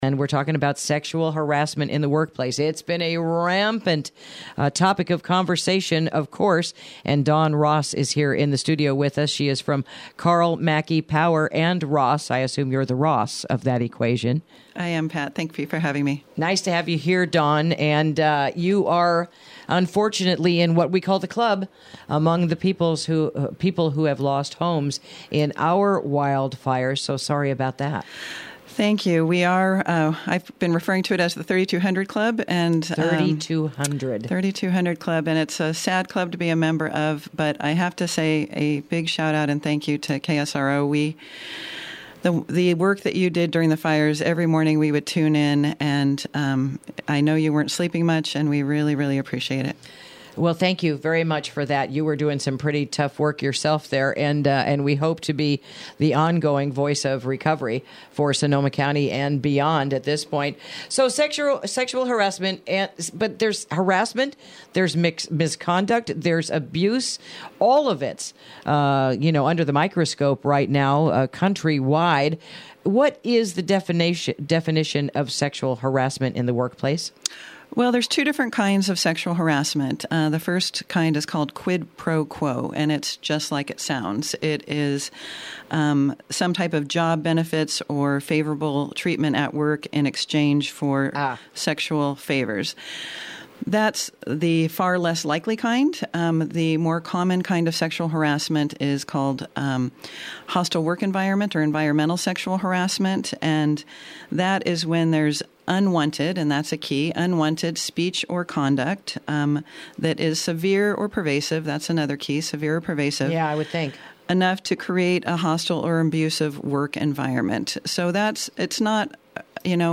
Interview: Sexual Harassment in the Workplace